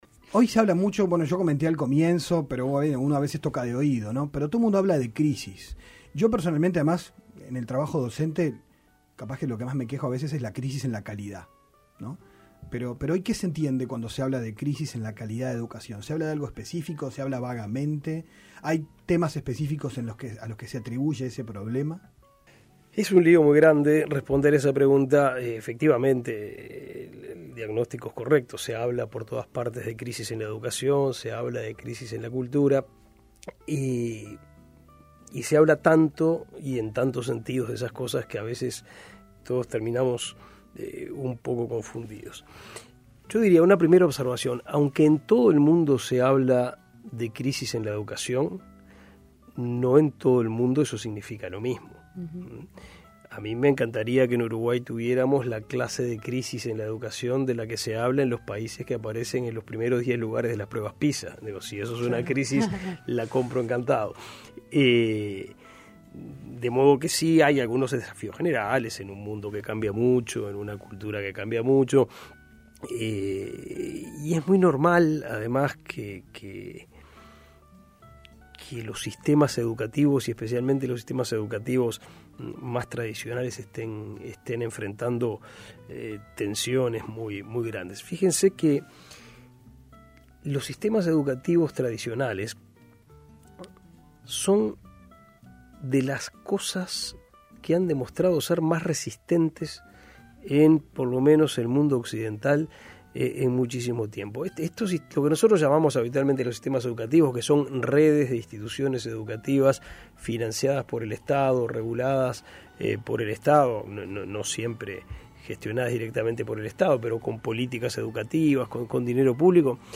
Entrevista al Dr. Pablo Da Silveira, Profesor de Filosofía Política y Director del Programa de Gobierno de la Educación en la Universidad Católica.